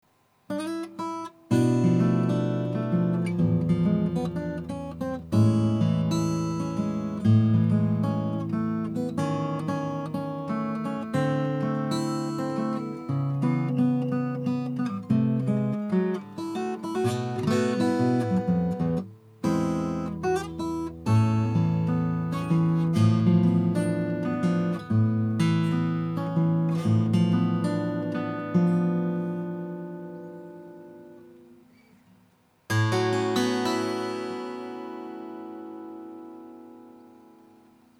オーナー様が演奏するリペア後の マーチンOM28Vの音 MP3
６弦側のネック起きが直って、低音と高音のバランスがきれいになり、本来のマーティンのバランスになっていたのが第一印象でした。
低音に節度が戻ったので、高音のメロディーも映えます。 リバーブもサスティンも増して、すばらしい音になったのがよくわかりました。